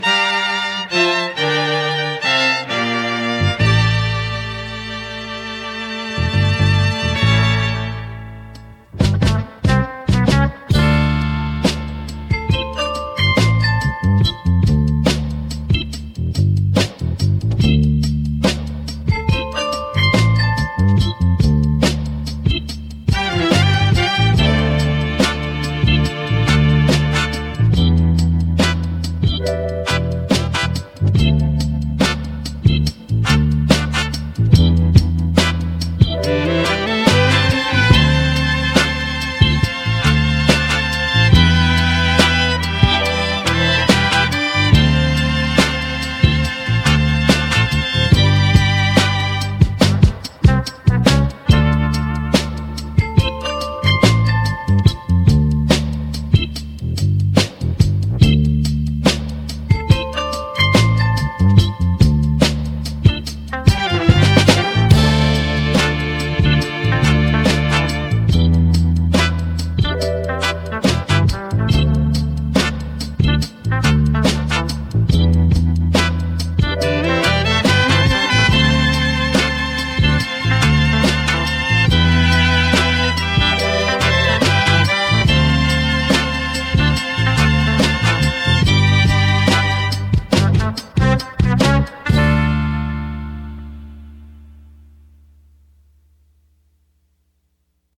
Mp3 demos of a few below.(Wav files will be better quality)